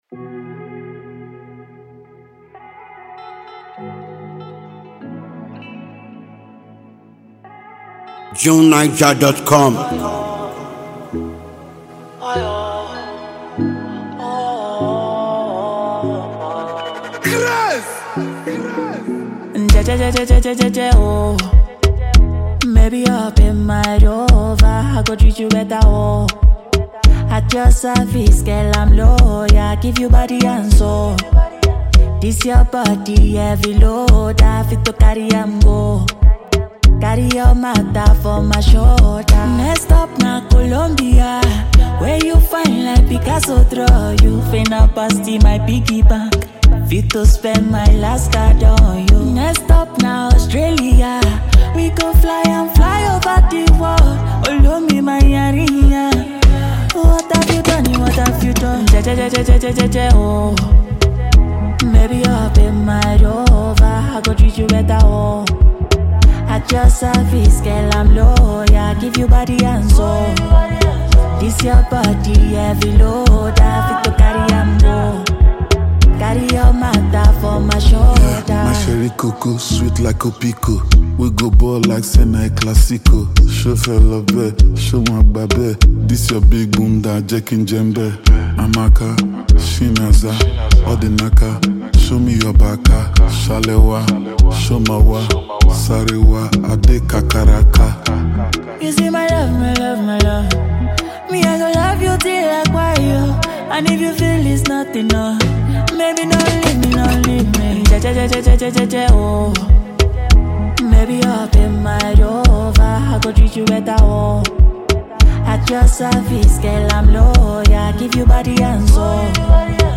seductive afrobeat love anthem
feel-good mood